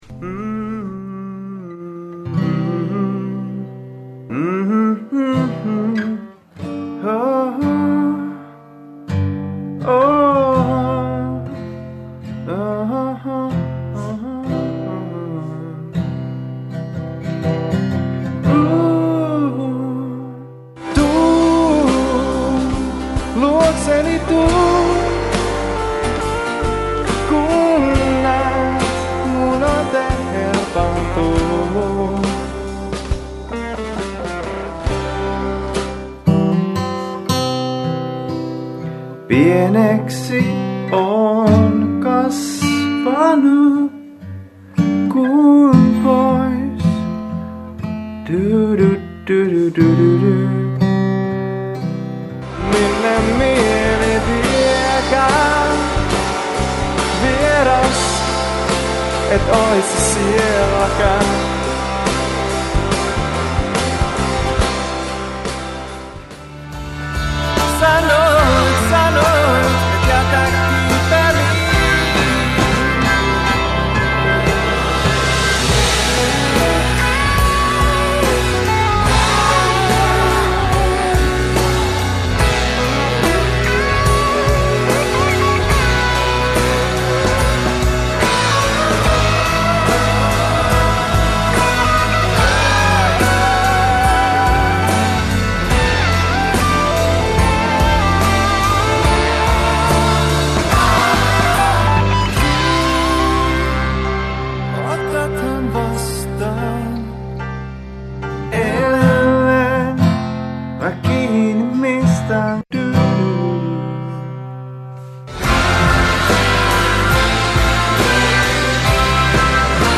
Radio Dein Kristillisen musiikin – keskusteluilta sai pohtimaan, miten taataa kristillisen musiikin julkaiseminen tulevaisuudessa.